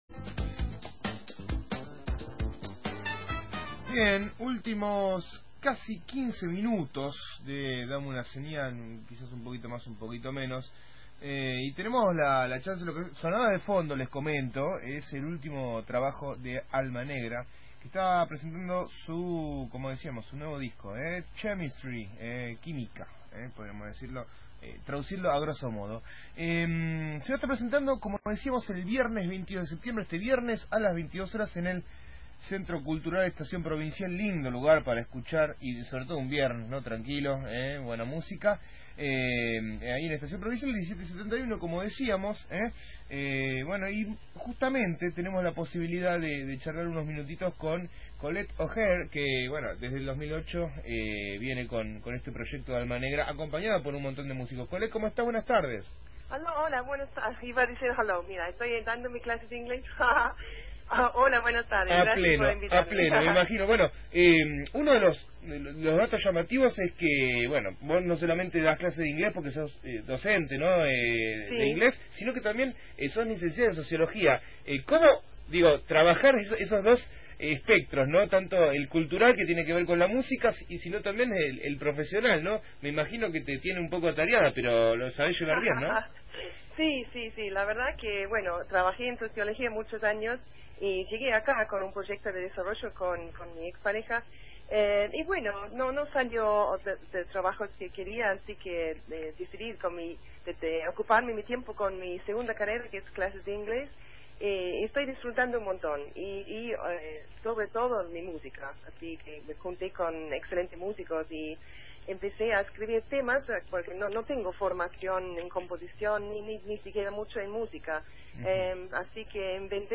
Este viernes, a las 22, se presenta la banda «Alma Negra» para dar a conocer su nuevo disco «Chimestry». Entrevista